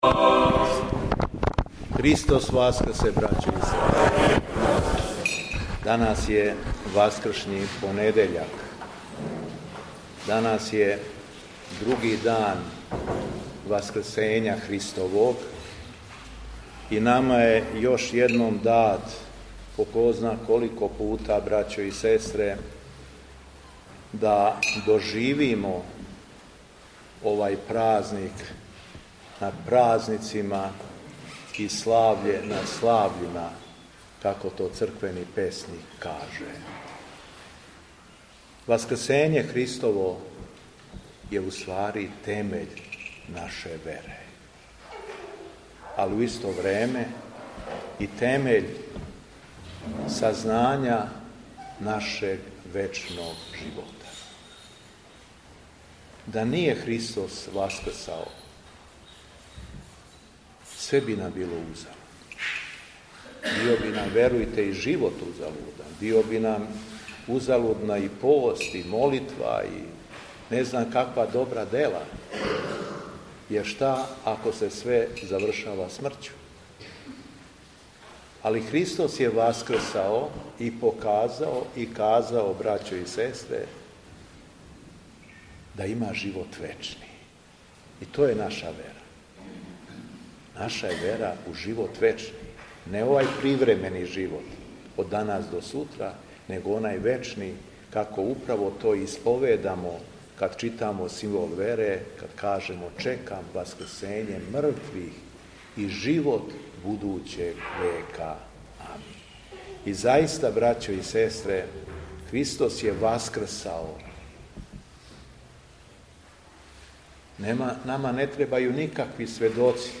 Беседа Његовог Високопреосвештенства Митрополита шумадијског г. Јована
У понедељак Светле седмице, други дан Васкрсења Христовог, 13. априла 2026. године, Његово Високопреосвештенство Архиепископ крагујевачки и Митрополит шумадијски служио је Свету Литургију у храму Светог великомученика Пантелејмона у Станову.